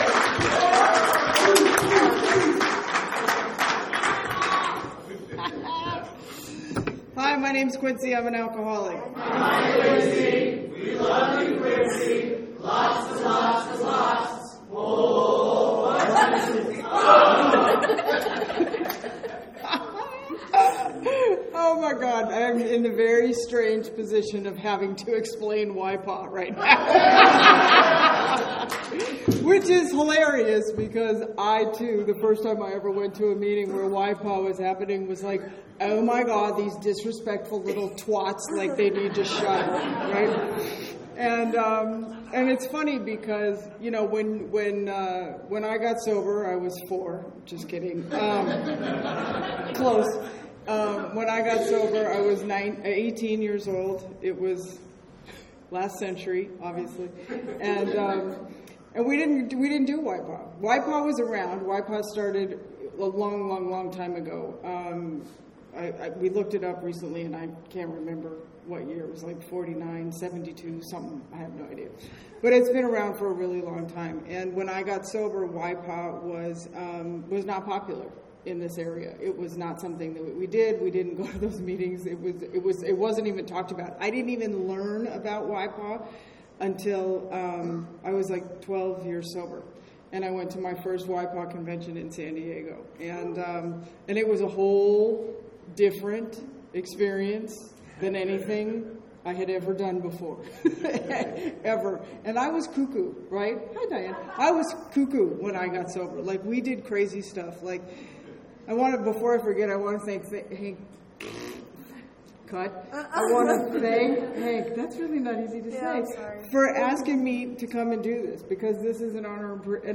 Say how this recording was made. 49th San Fernando Valley Alcoholics Anonymous Convention